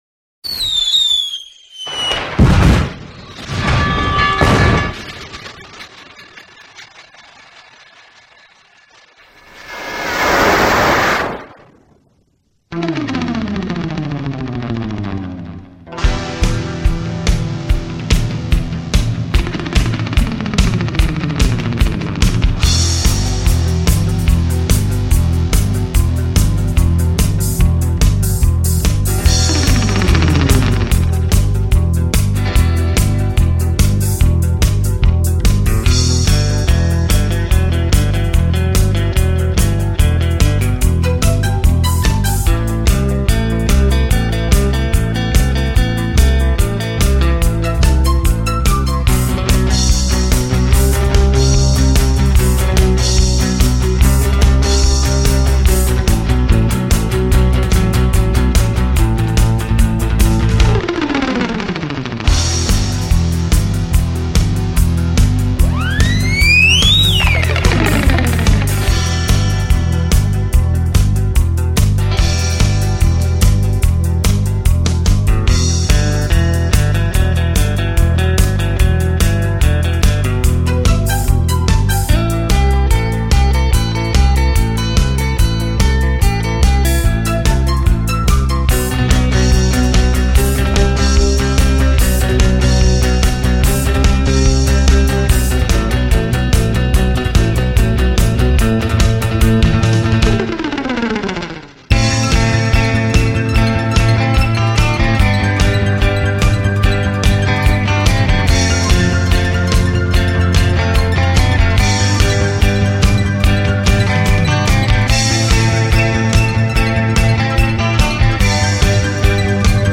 首创杜比环绕音效录制
专辑格式：DTS-CD-5.1声道